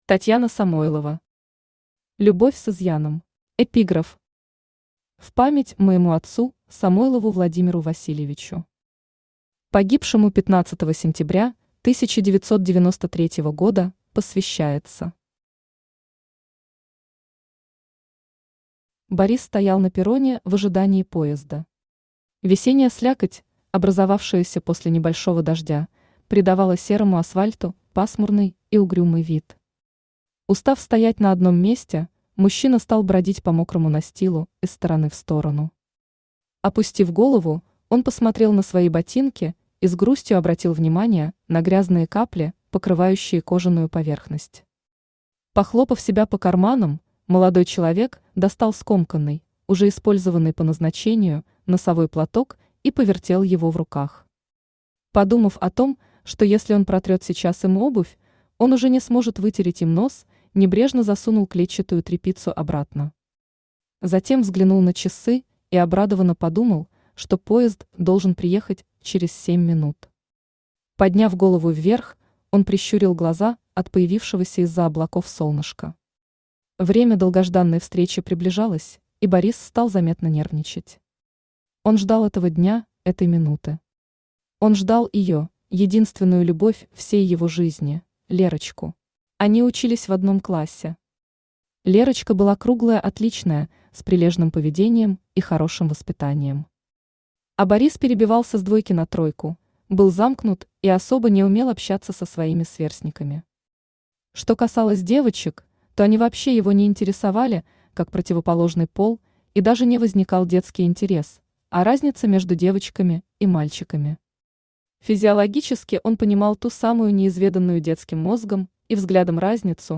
Aудиокнига Любовь с изъяном Автор Татьяна Владимировна Самойлова Читает аудиокнигу Авточтец ЛитРес.